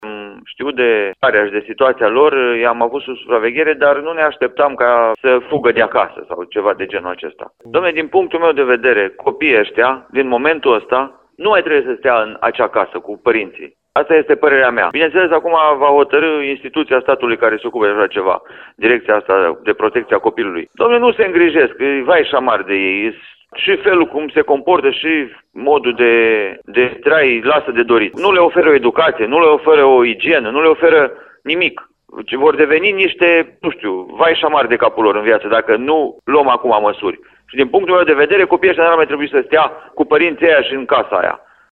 Copiii sunt, în momentul de față, la un asistent maternal, iar primarul comunei, Petru Bardac, subliniază că micuţii nu ar mai trebui să fie lăsați cu părinții naturali.